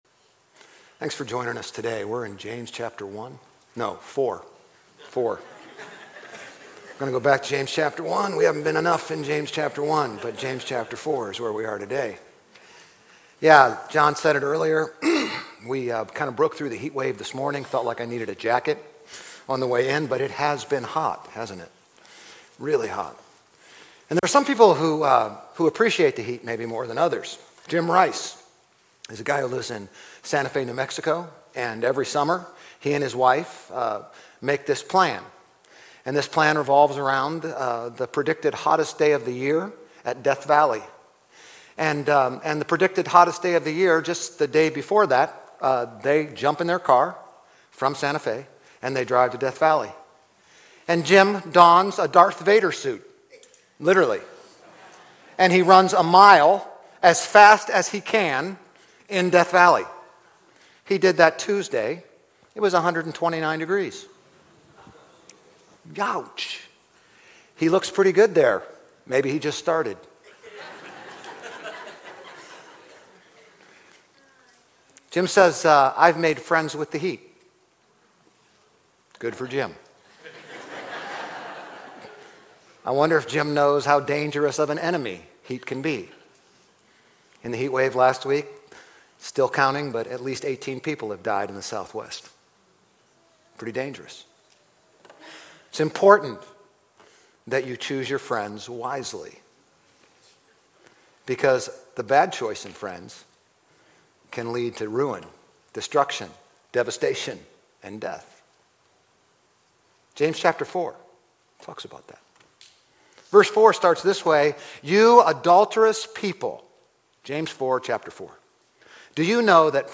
Grace Community Church Old Jacksonville Campus Sermons James 4:1-12 Jul 23 2023 | 00:30:44 1x 00:00 / 00:30:44 Subscribe Share RSS Feed Share Link Embed